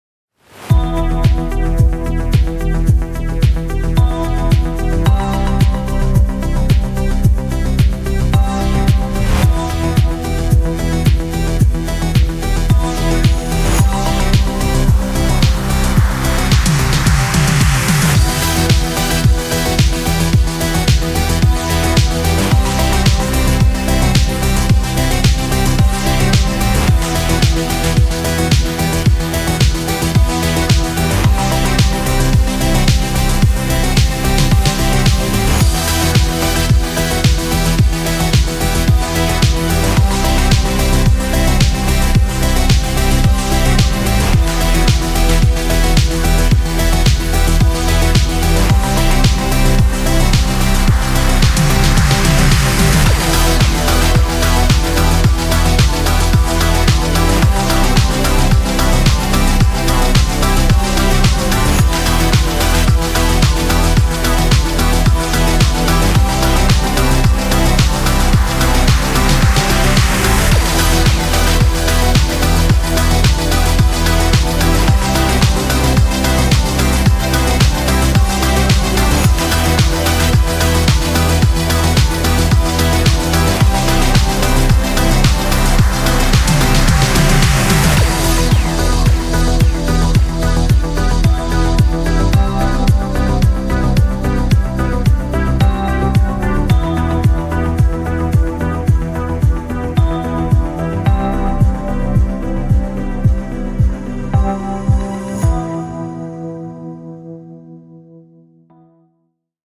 11 - Synthwave Deep Ambient